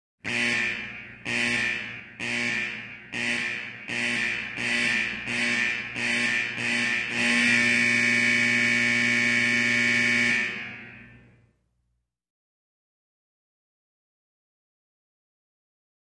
Medium Pitched Buzz, Close Perspective, Long Constant.